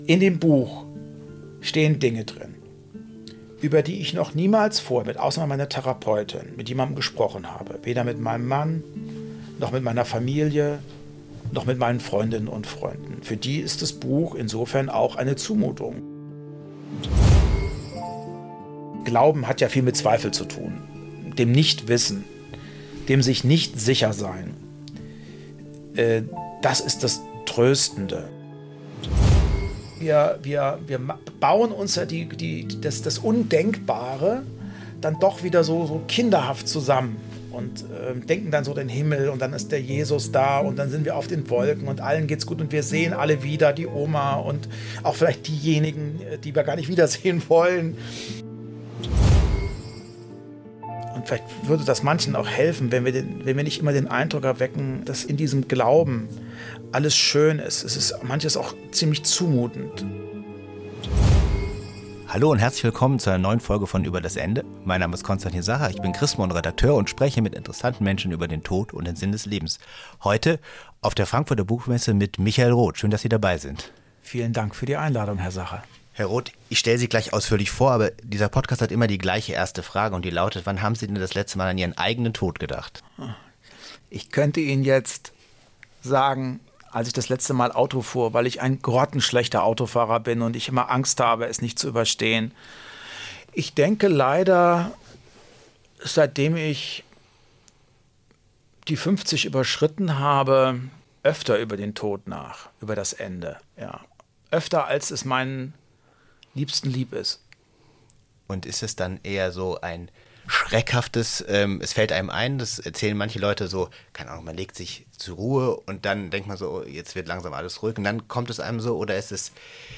Im Gespräch berichtet Michael Roth vom Tod seiner Eltern, seinen Ängsten und seinem Glauben.